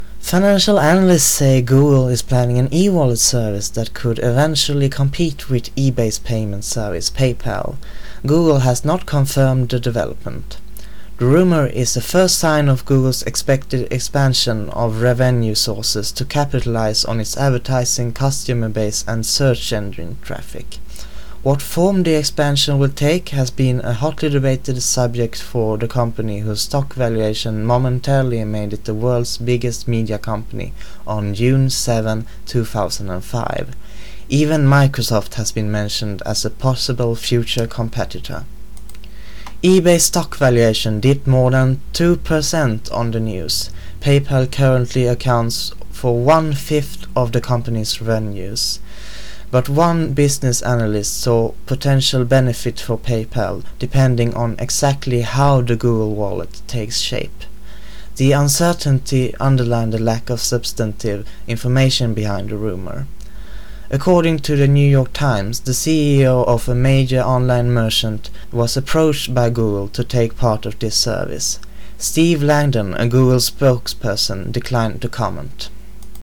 Not perfect, but it's just a test.